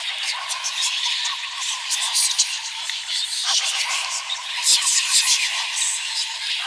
rogue_skill_sneak.wav